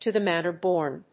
アクセント(as [as ìf]) to the mánner bórn